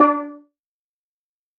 Steel Drum.wav